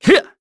Zafir-Vox_Jump_kr.wav